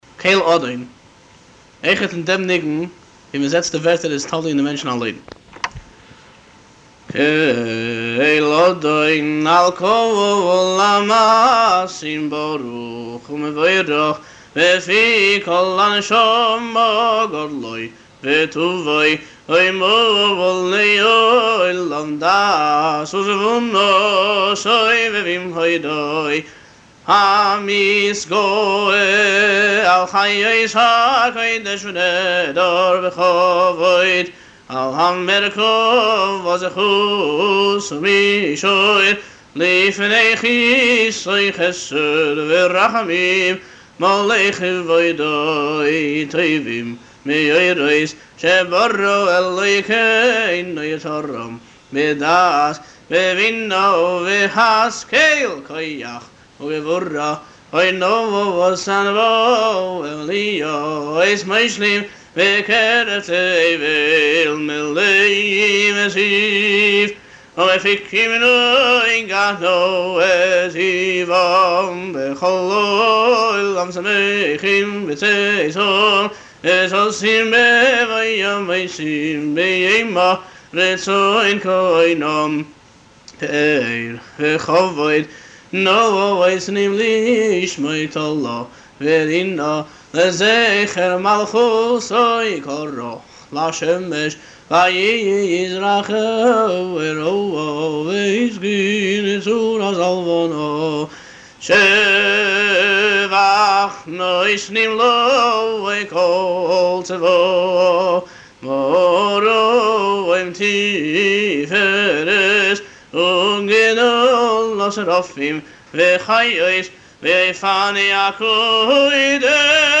הלחן במסורת הניגונים